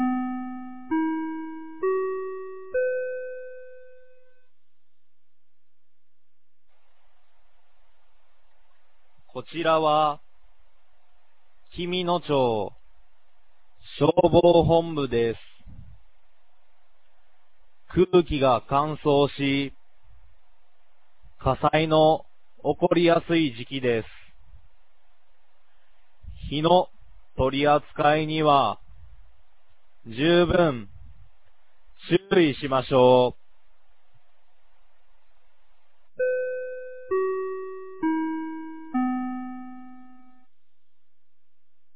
2025年11月01日 16時00分に、紀美野町より全地区へ放送がありました。